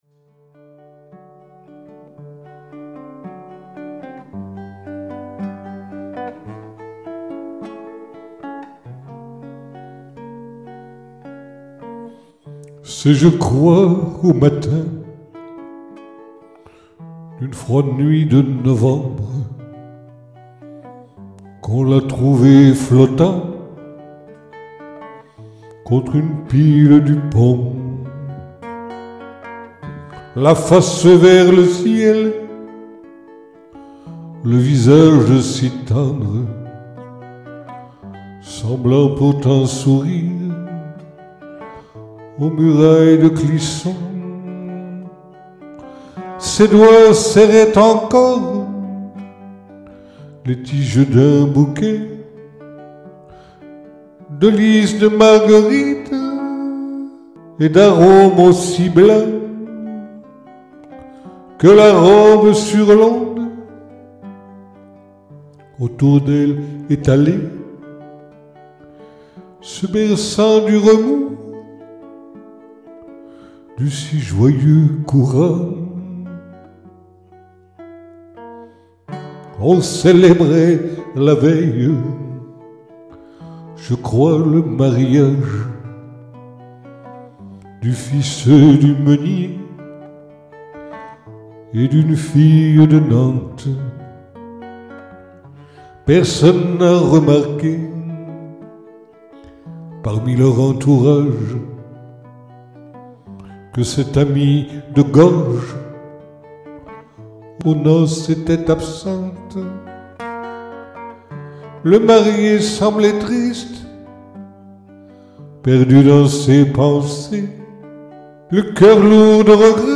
738 (25) - À Clisson [Capo 2°] (Autres) 20 oct. 2019